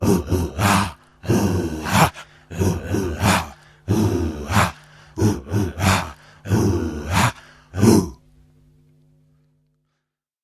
Звук песни племени